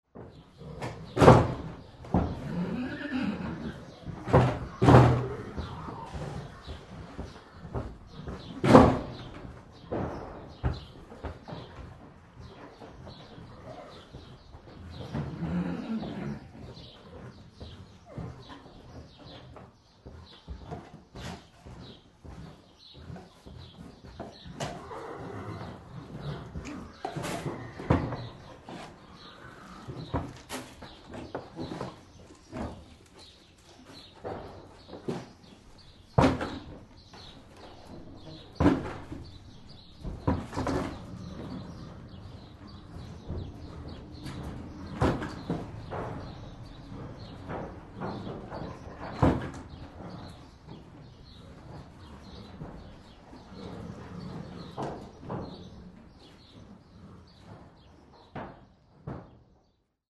На этой странице собрана коллекция натуральных звуков ржания лошадей.
Тихий гул конюшни с лошадьми